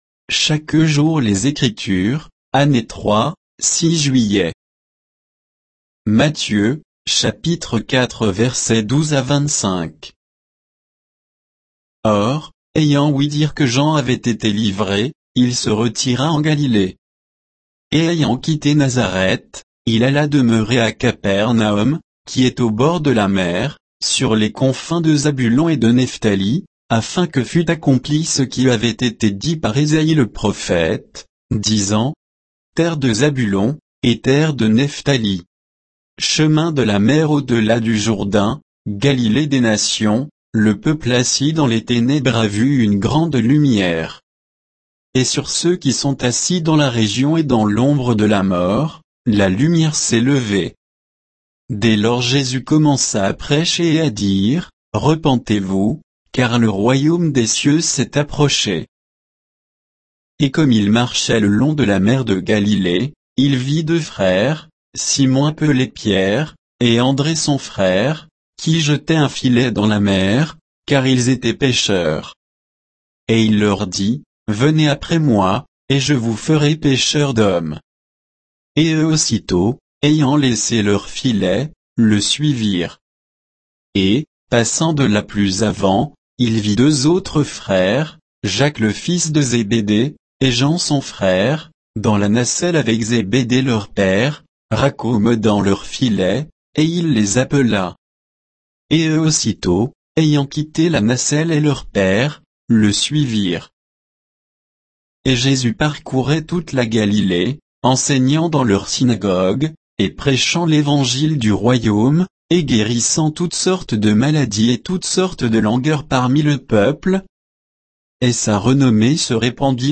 Méditation quoditienne de Chaque jour les Écritures sur Matthieu 4, 12 à 25